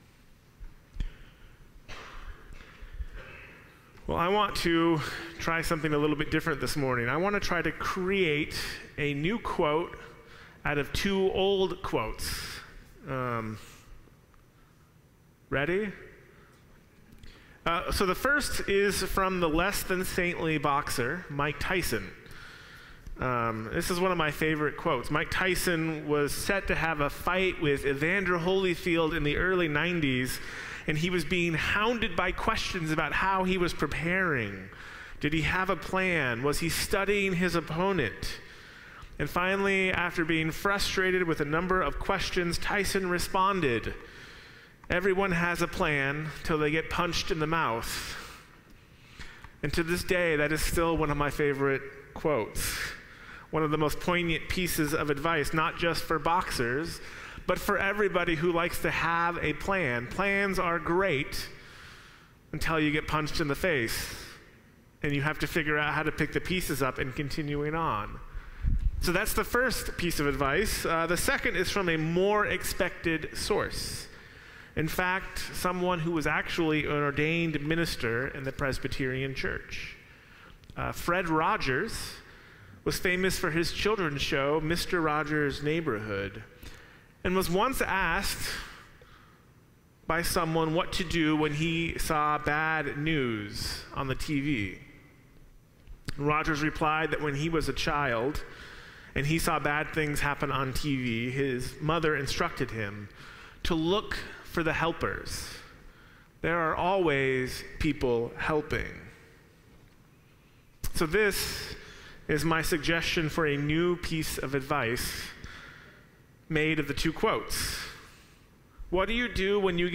Sermons | First Christian Church